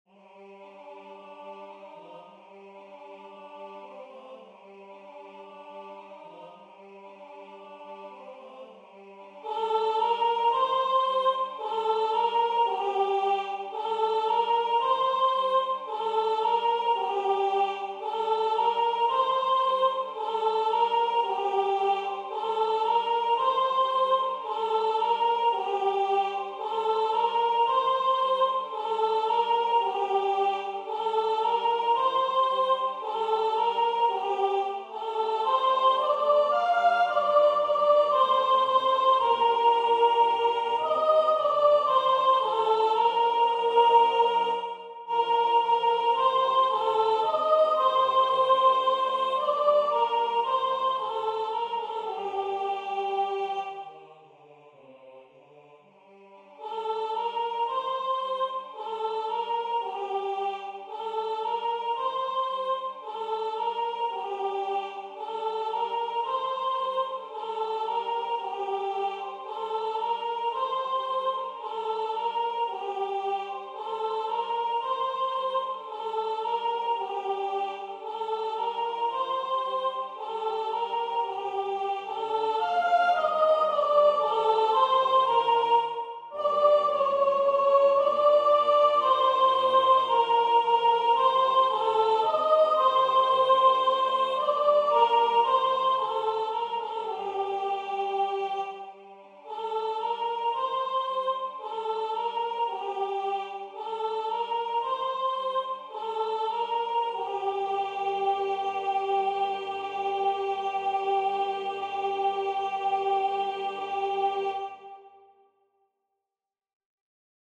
Soprano Voix Synth